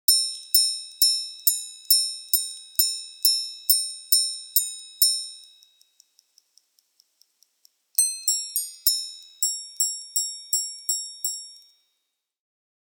The melodic sequence of this chime with three hammers striking three tones plays the note C for the hours, the notes E D C for the quarters and the note E for the minutes.
• minute repeater,
• three-hammer carillon tourbillon and power-reserve indicator;